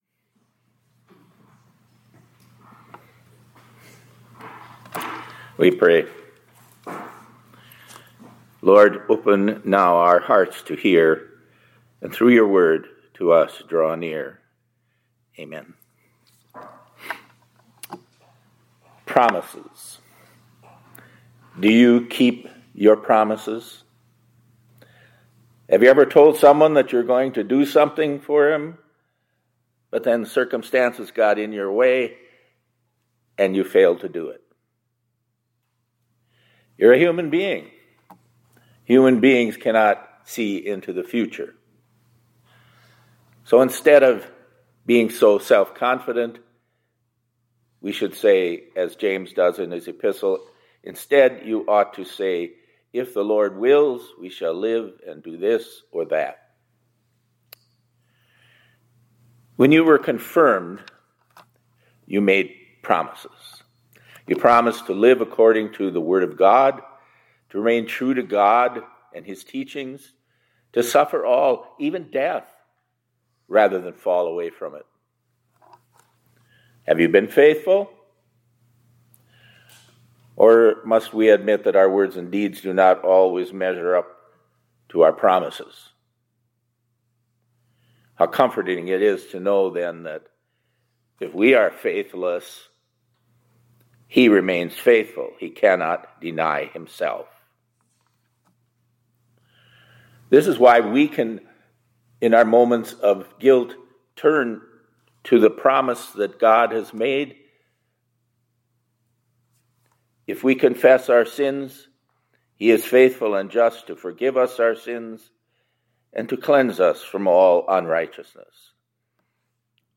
2026-03-31 ILC Chapel — He Who Promised Is Faithful